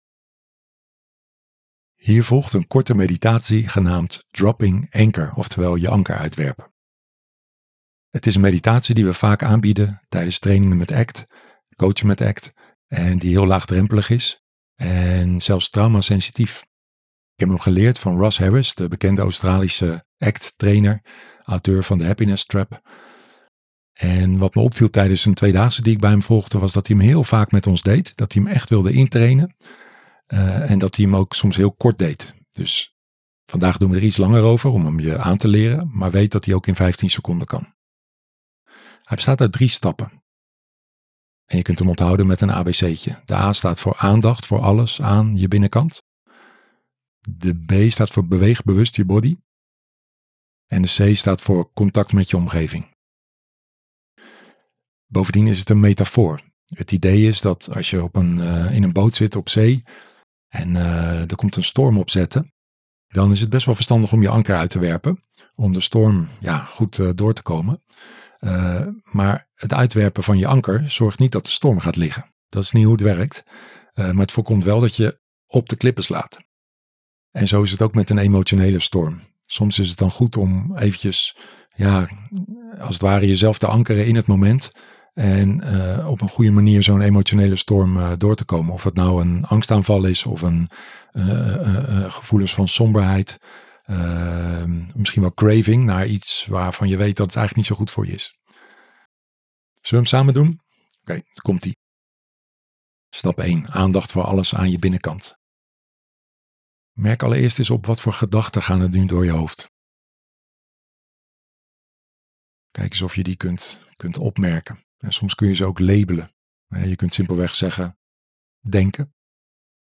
Omdat ik merk hoeveel rust, helderheid en stevigheid deze oefening geeft, heb ik ‘m ingesproken voor mijn podcast.